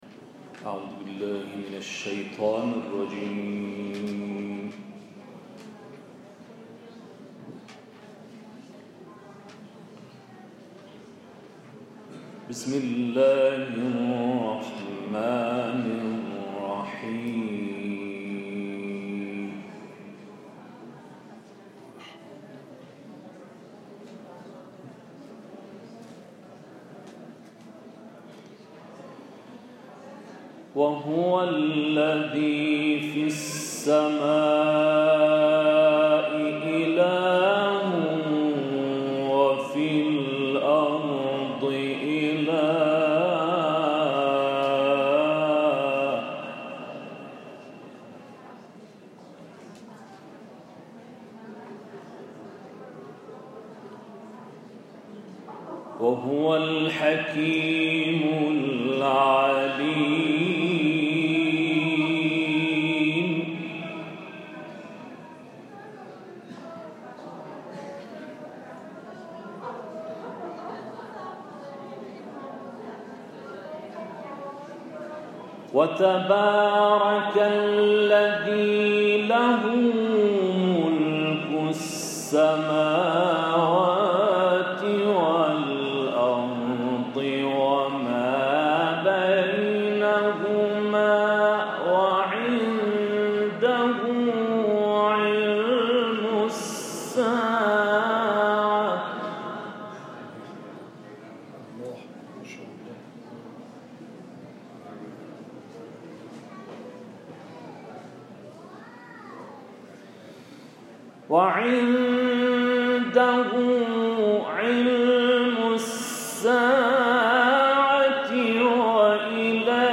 تلاوت
قاری بین المللی